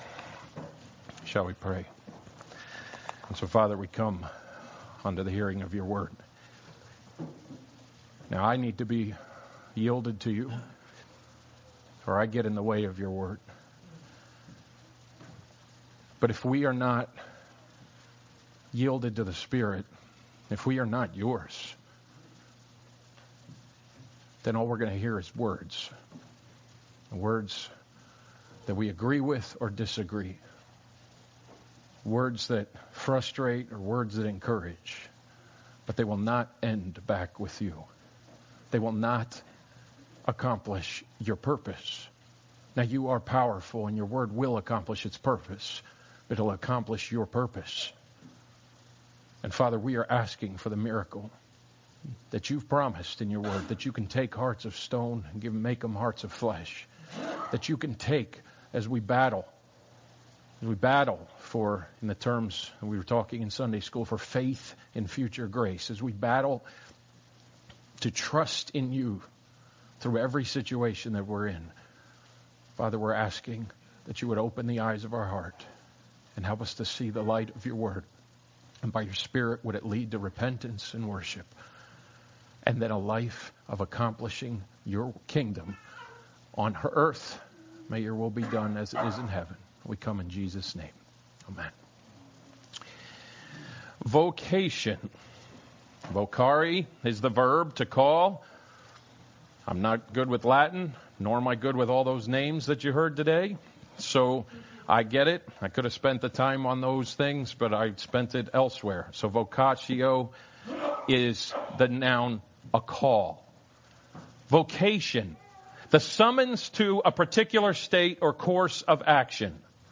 Francis A. Schaeffer, The Great Evangelical Disaster Sermon Notes Subscribe to Updates Email Subscribe to: Sermons Prayers Blog Posts Events Submit SHARE ON Twitter Facebook Buffer LinkedIn Pin It